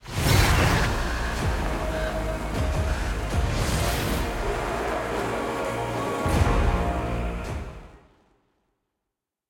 sfx-nacho-intro-skin-mordekaiser-anim.ogg